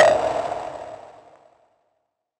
Perc [ Pow ].wav